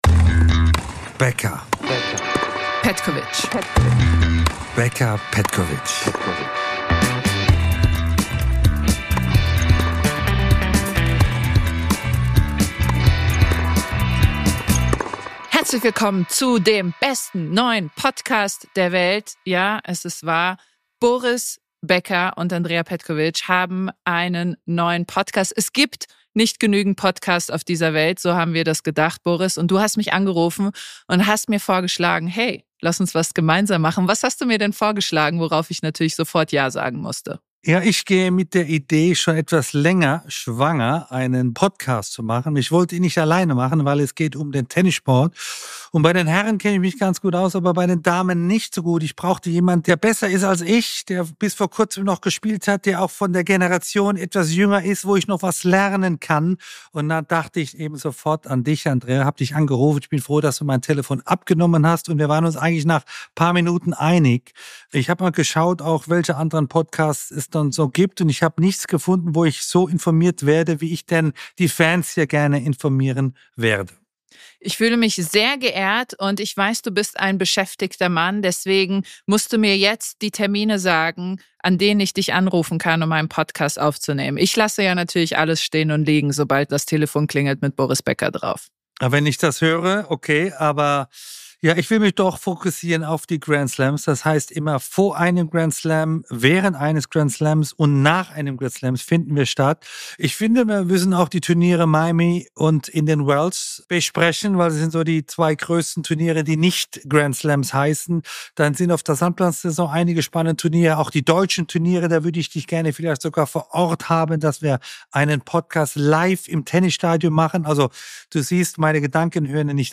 Der neue Podcast von Boris Becker und Co-Moderatorin Andrea